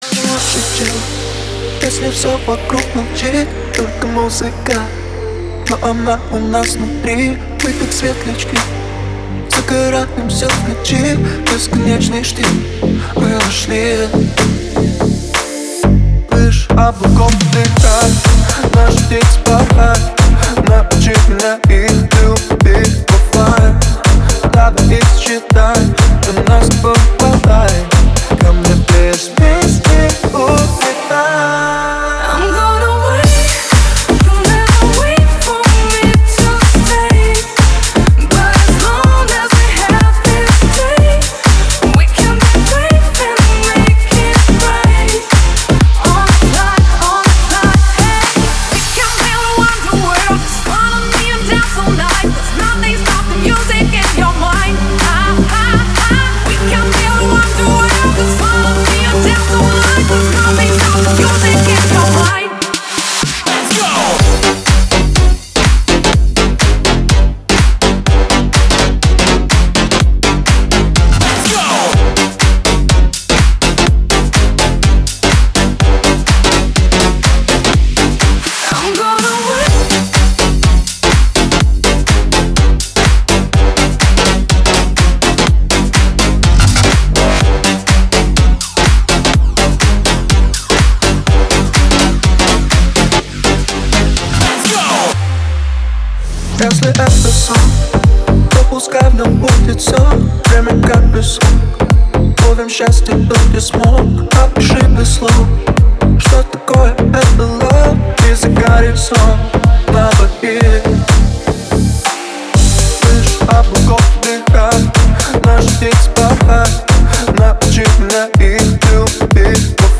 сочетая их уникальные голоса и динамичные ритмы.